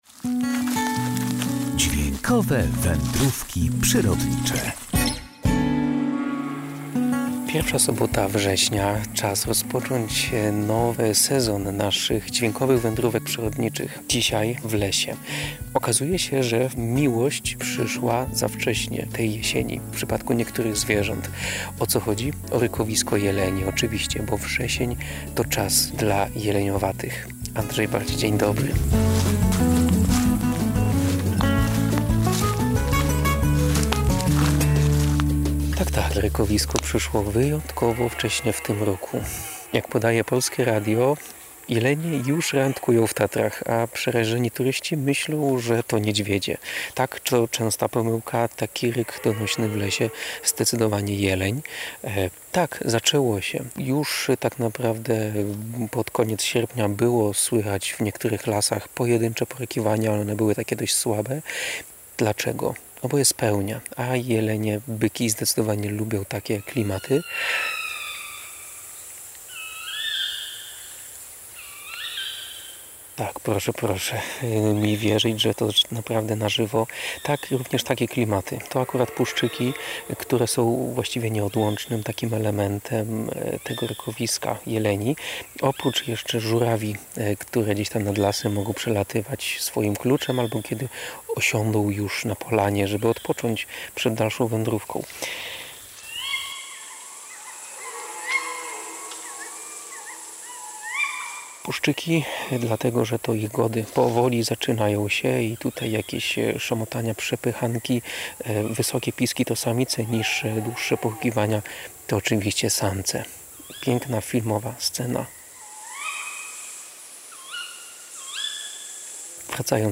Pierwsza sobota września za nami, a więc czas rozpocząć nowy sezon Dźwiękowych Wędrówek Przyrodniczych, tym razem w lesie. Okazuje się, że miłość w przypadku niektórych zwierząt przyszła za wcześnie tej jesieni. Chodzi o rykowisko jeleni.